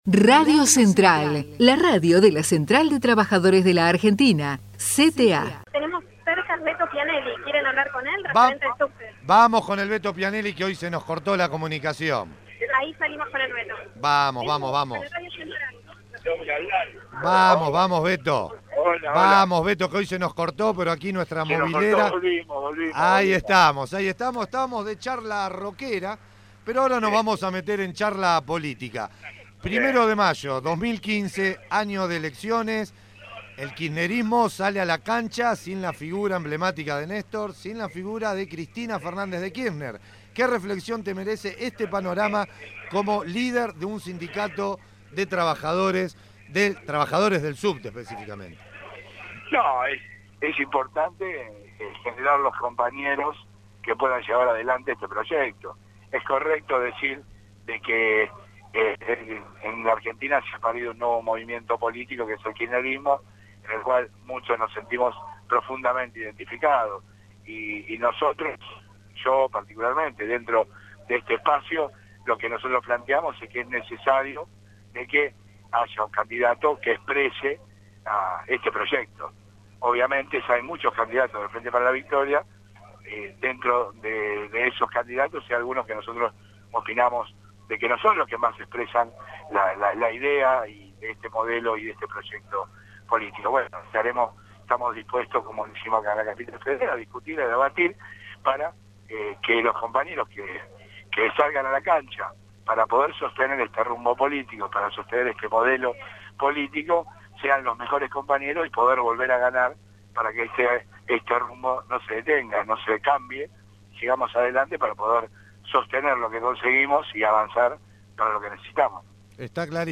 ACTO 1º de MAYO - LUNA PARK